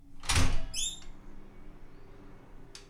household
Window Aluminum Open Squeek 2